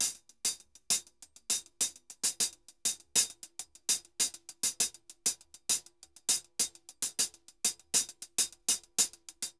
HH_Candombe 100_1.wav